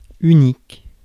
Prononciation
Synonymes singulier Prononciation France: IPA: [y.nik] Le mot recherché trouvé avec ces langues de source: français Traduction 1.